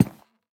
Minecraft Version Minecraft Version snapshot Latest Release | Latest Snapshot snapshot / assets / minecraft / sounds / block / cherry_wood_hanging_sign / step2.ogg Compare With Compare With Latest Release | Latest Snapshot